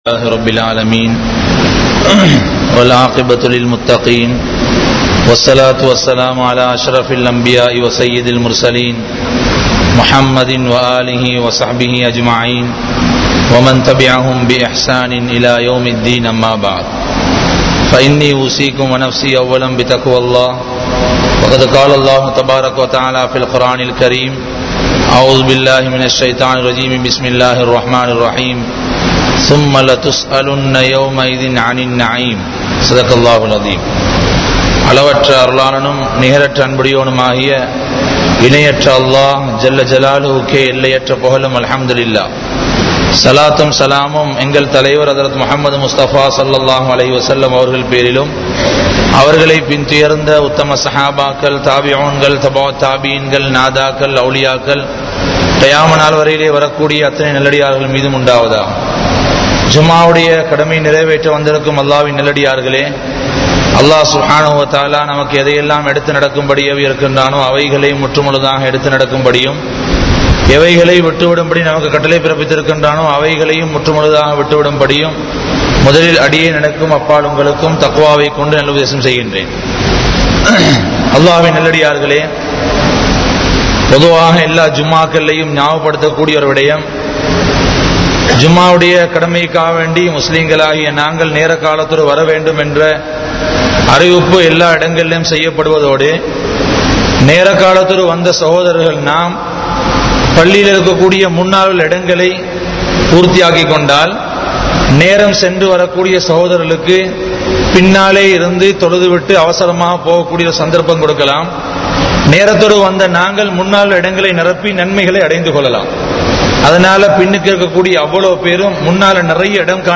Moasadi Seium Manitharhal (மோசடி செய்யும் மனிதர்கள்) | Audio Bayans | All Ceylon Muslim Youth Community | Addalaichenai
Colombo 02, Wekanda Jumuah Masjidh